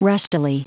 Prononciation du mot rustily en anglais (fichier audio)
Prononciation du mot : rustily